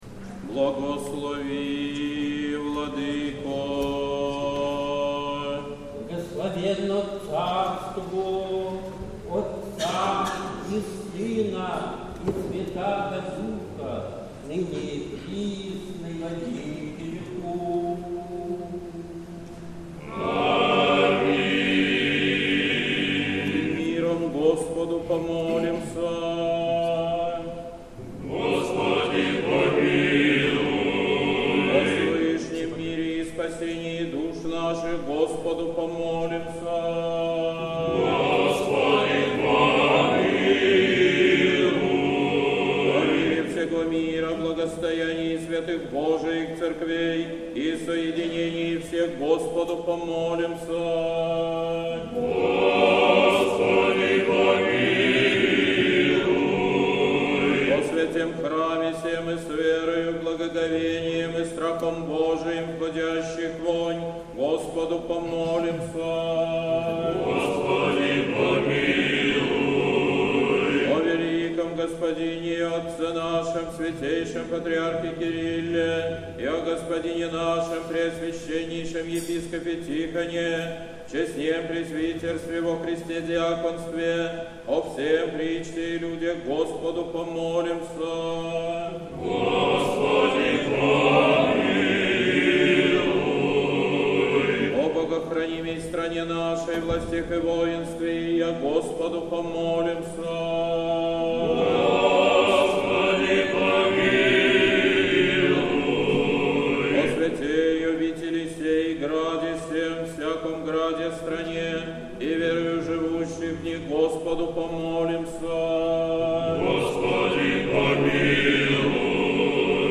Божественная литургия. Хор Сретенского монастыря.
Божественная литургия в Сретенском монастыре в Неделю 7-ю по Пятидесятнице